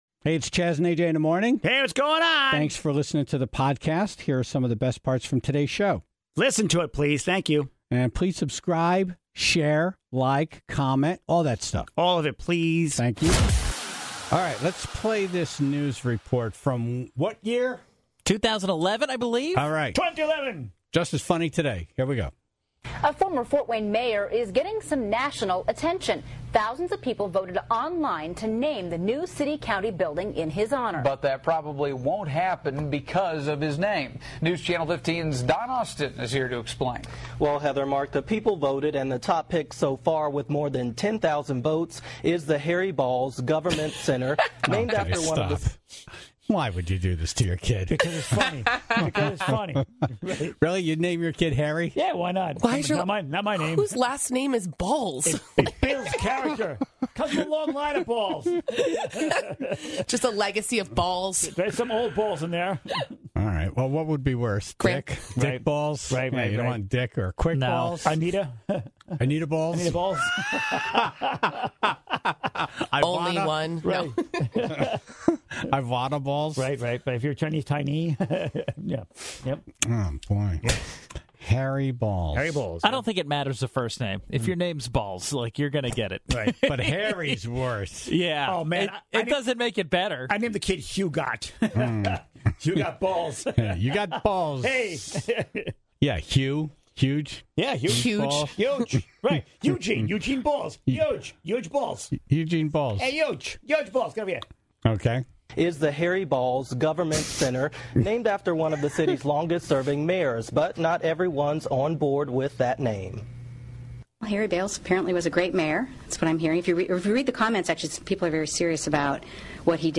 (7:56) The Tribe was asked to call in their neighbors from hell, and took the assignment a little too seriously.